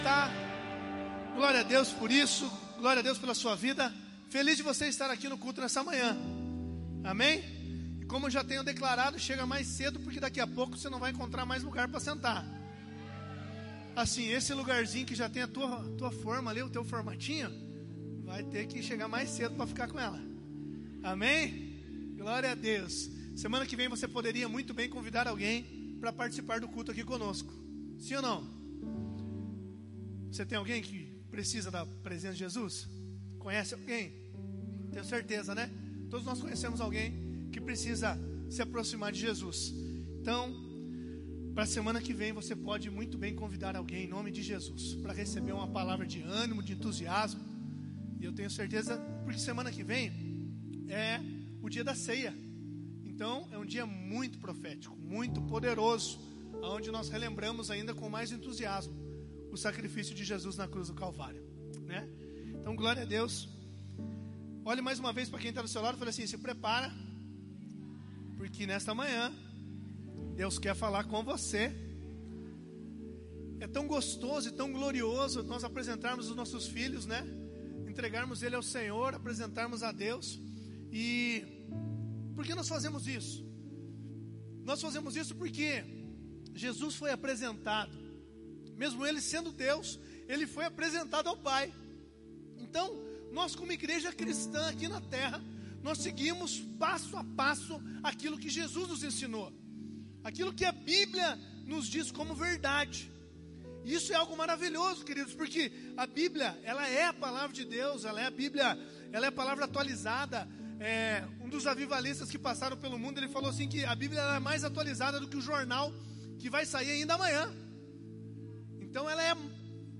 ICP - Igreja Cristã Presbiteriana